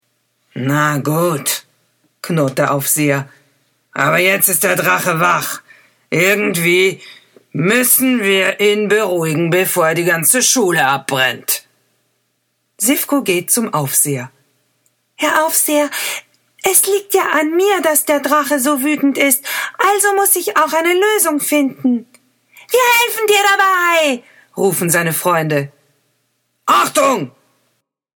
Voix off
10 - 80 ans - Mezzo-soprano